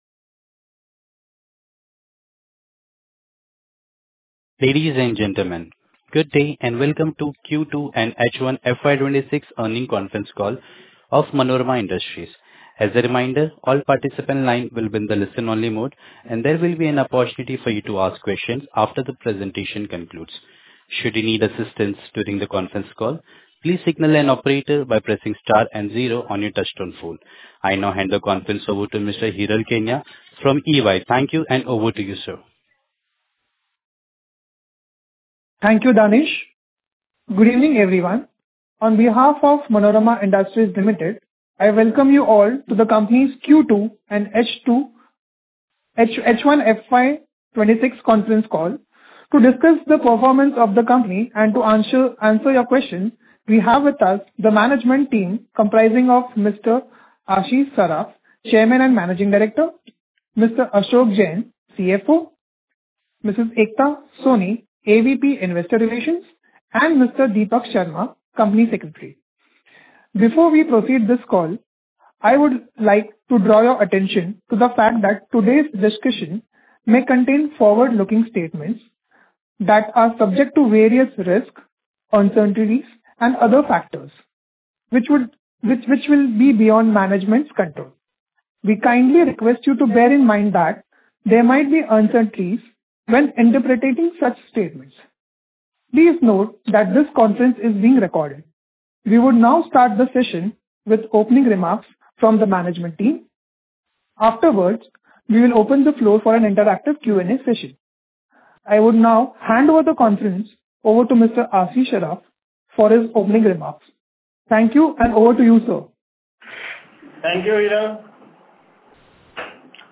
Concalls
Earnings-call-Q2-H1-FY26.mp3